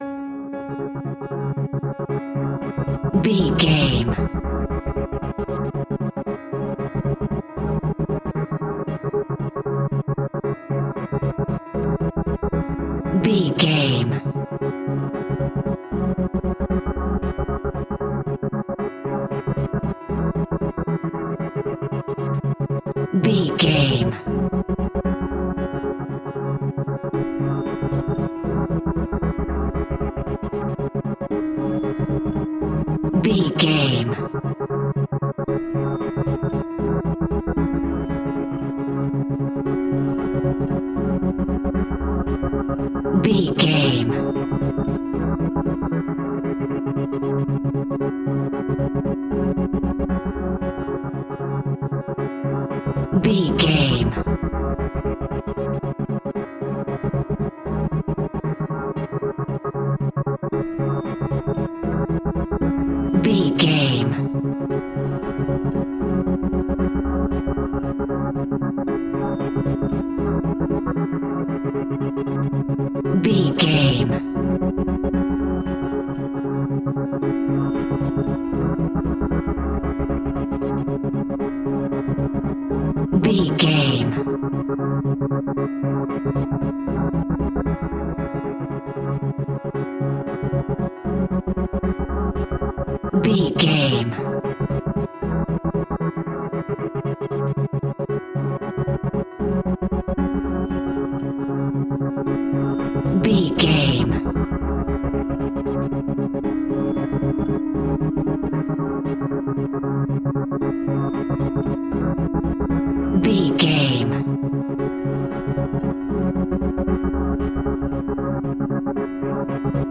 Horror Chase Scene Music Cue.
Aeolian/Minor
C#
tension
ominous
dark
eerie
synthesiser
piano
ambience
pads